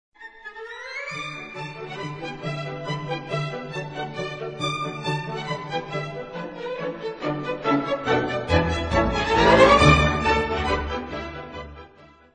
Titelmusik zur gleichnamigen Fernsehserie
Besetzung: 2, 2, 2, 2 – 4 – 3, 3, Pk., Schl., Hfe., Str.